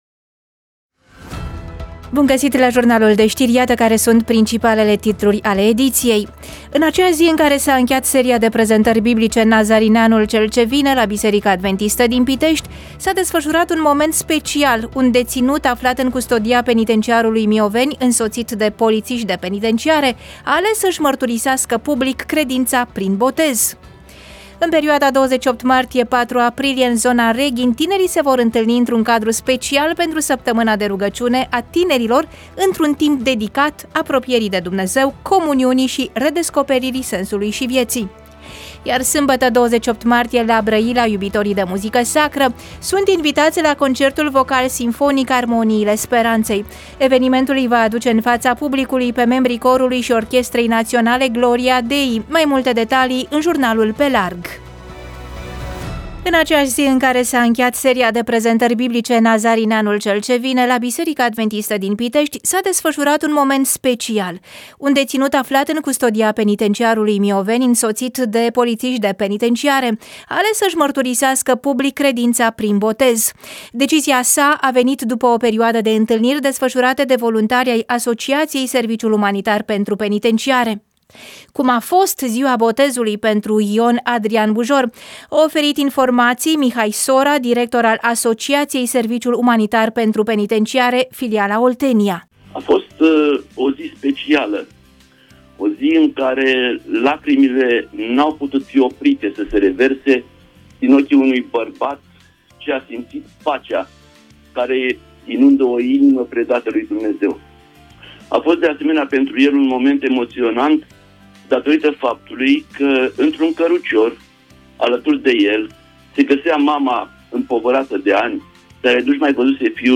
EMISIUNEA: Știri Radio Vocea Speranței